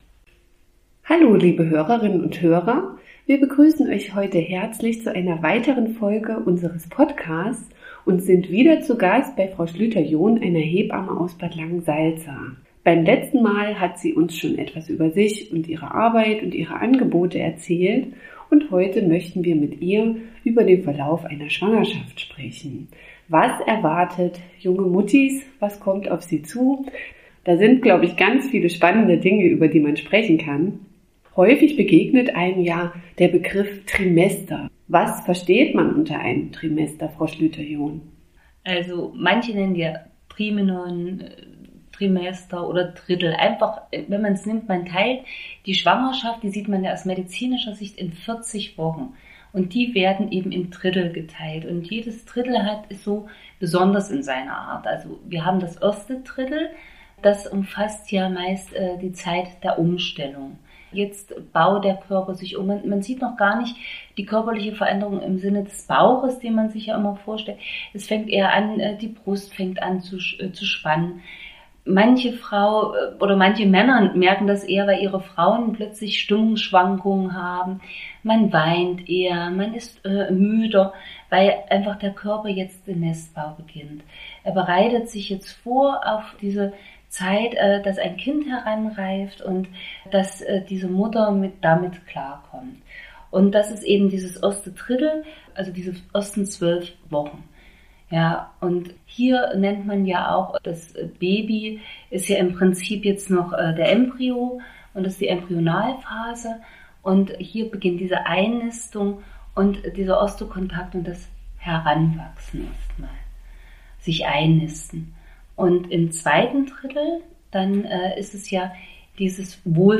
Gespräch mit einer Hebamme Teil 2 ~ AWO Family Link Podcast